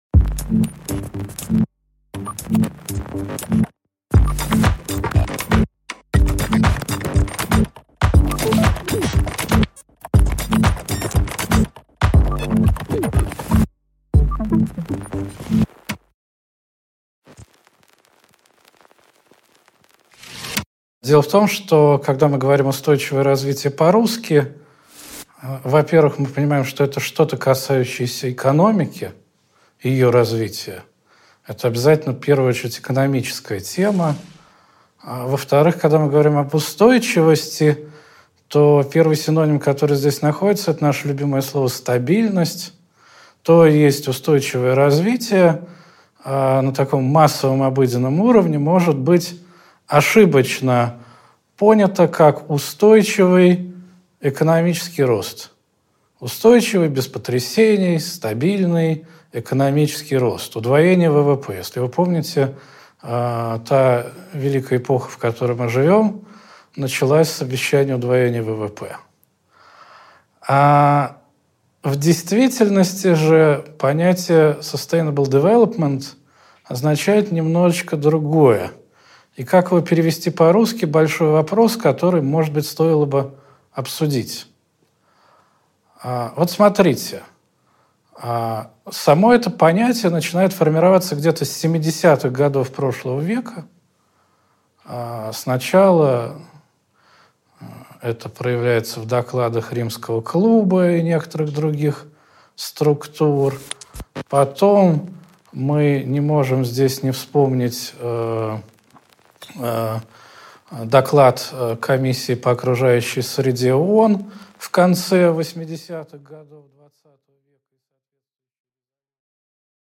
Аудиокнига Не только богатеть | Библиотека аудиокниг